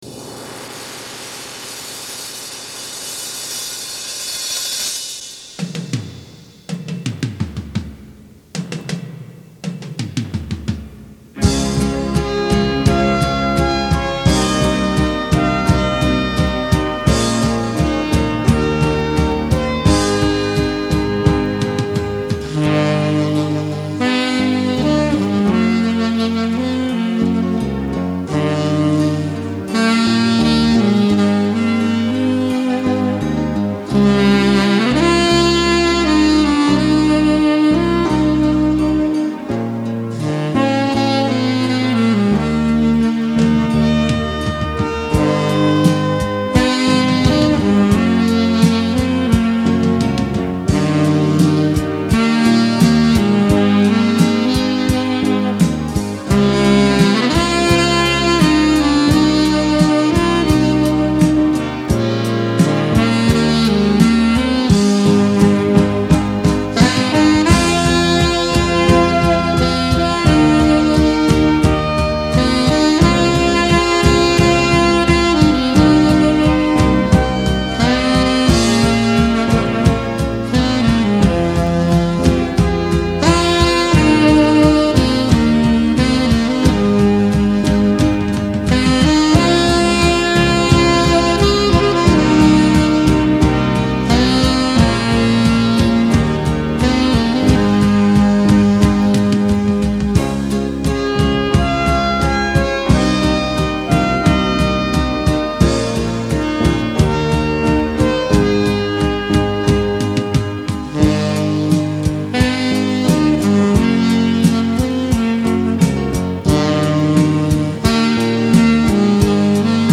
优美激情的萨克斯风娓娓诉说，港台民歌委婉旋律悠然的飘过；
道出凄婉与忧伤的恋恋不舍情，依依惜别后君再何时重聚今宵。
磁带数字化